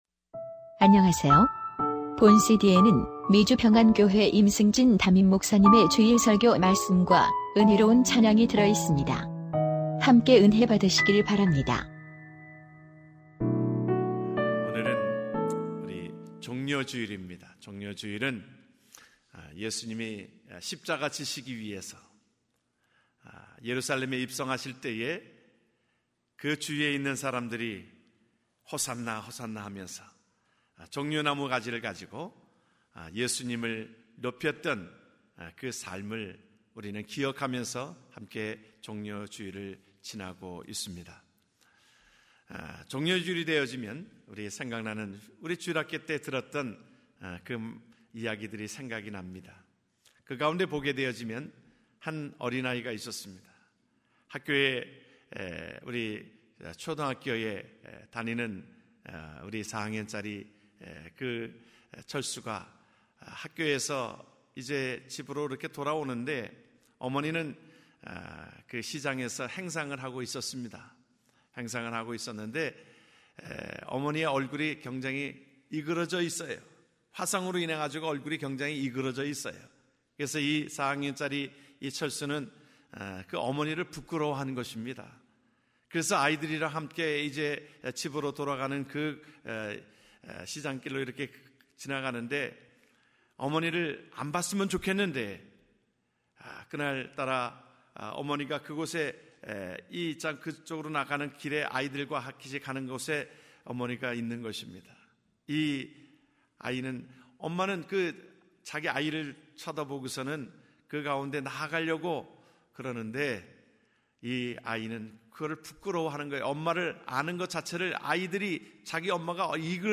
이번주 설교는 오디오로만 제공됨을 양해해 주시길 바랍니다.